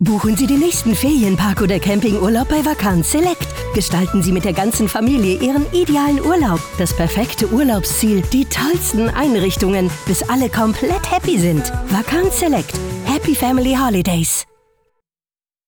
Duitse commercial